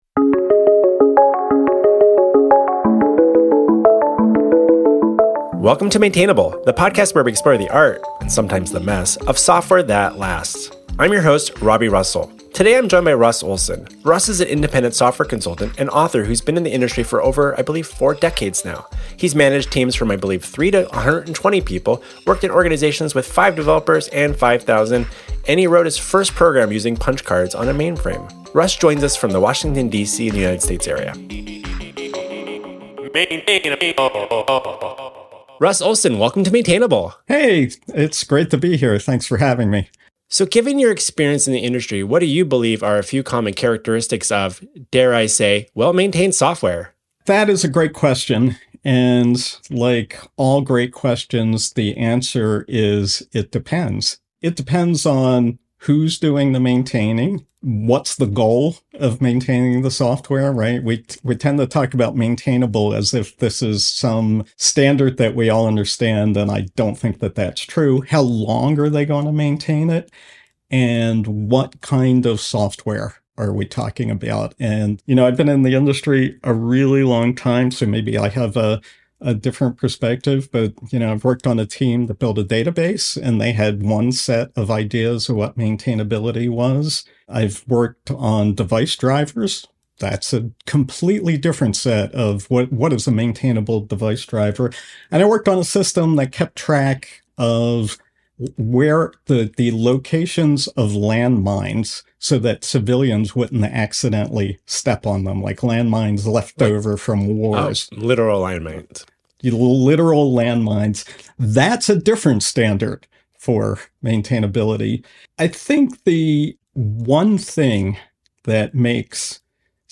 It’s a conversation about legacy systems, rewrites, developer quality of life, and the stories software teams need to tell each other better.